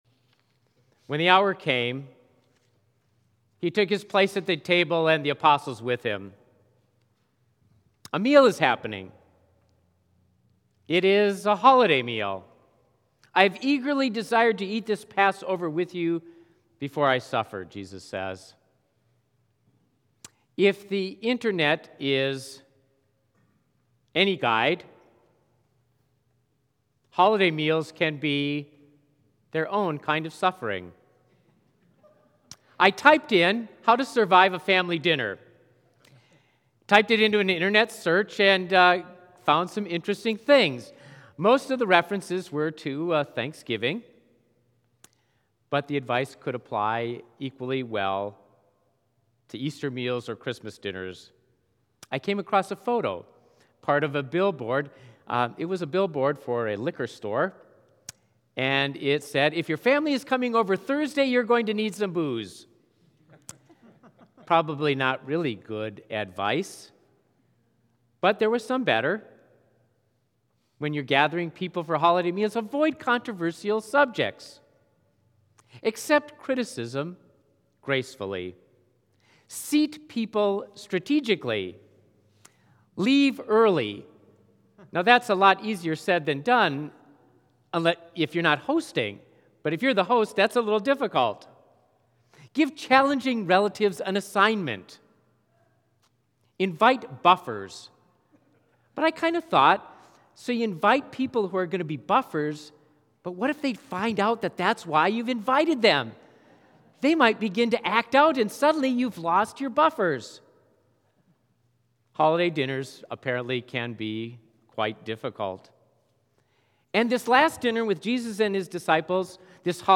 Sermon preached Holy Thursday, March 24, 2016